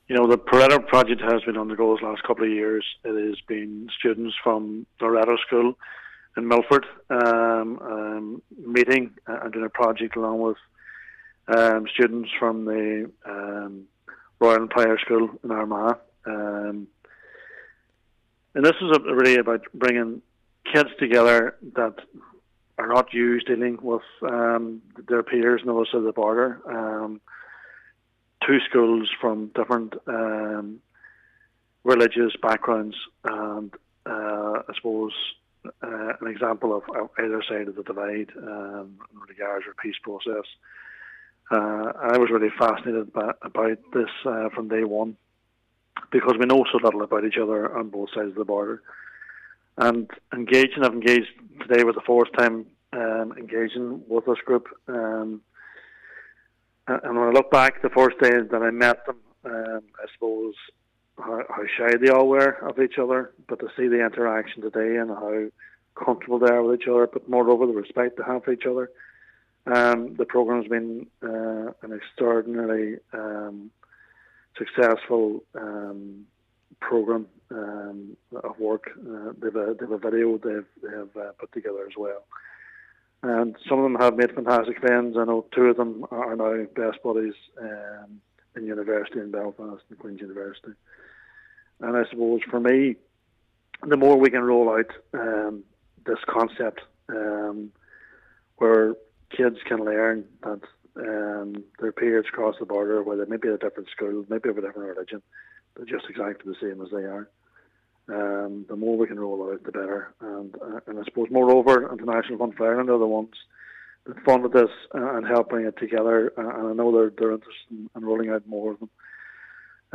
Senator Blaney says if projects like this are rolled out on a wider scale, border polls will become a thing of the past: